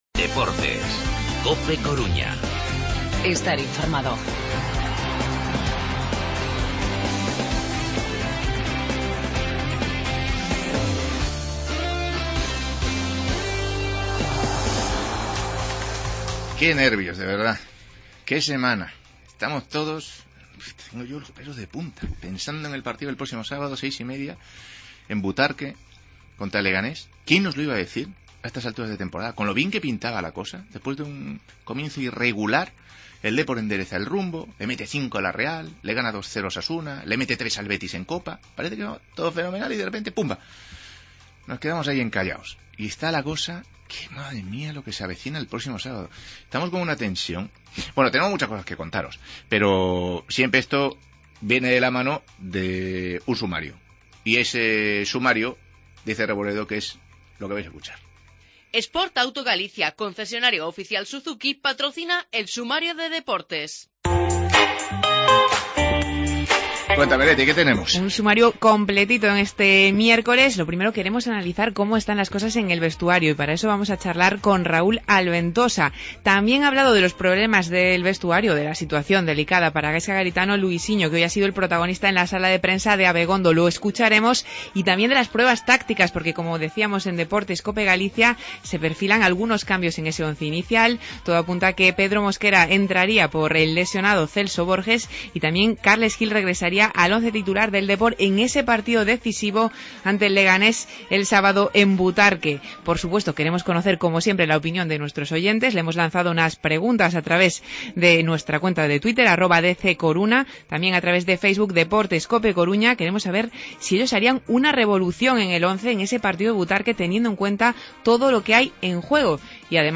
Redacción digital Madrid - Publicado el 22 feb 2017, 16:07 - Actualizado 19 mar 2023, 02:54 1 min lectura Descargar Facebook Twitter Whatsapp Telegram Enviar por email Copiar enlace Entrevistamos a Raúl Albentosa, escuchamos a Luisinho en sala de prensa y el posible once para la visita al Leganés del sábado en Butarque.